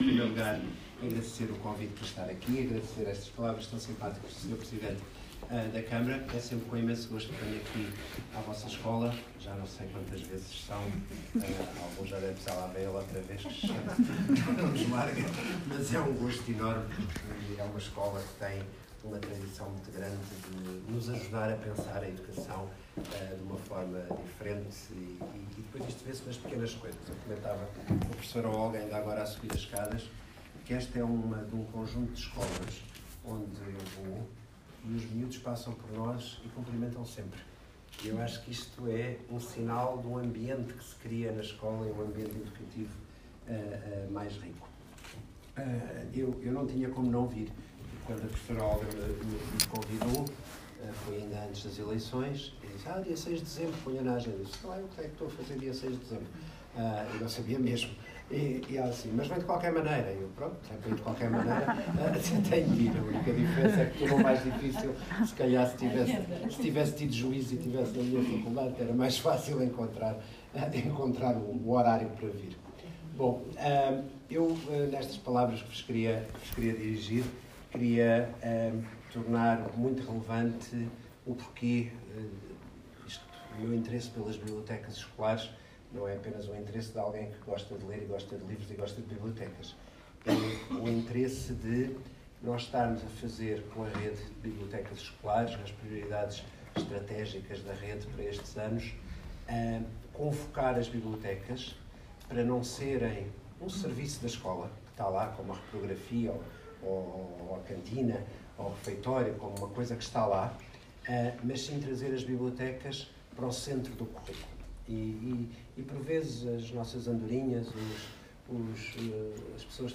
III Encontro da Rede de Bibliotecas do Concelho de Constância | sessão de encerramento | João Costa by Rede de Bibliotecas Escolares - Portugal